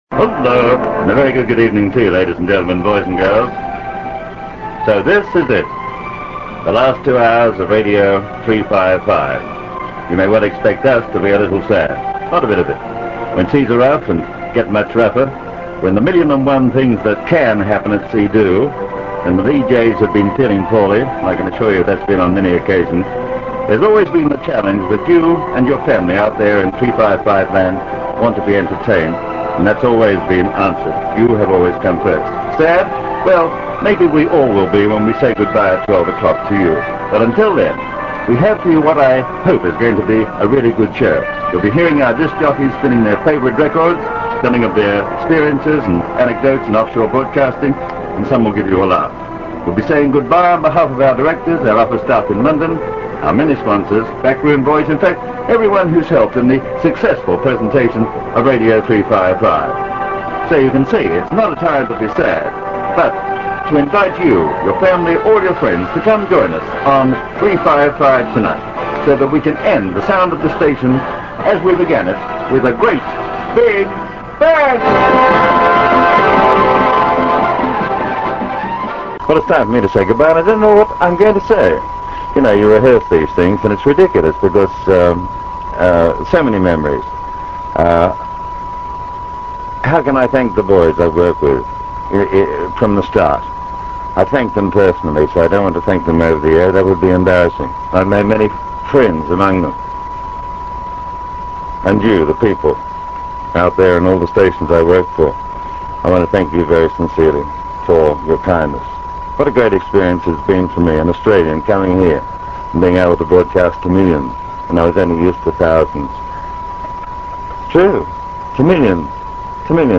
opening the programme and saying his goodbyes two hours later.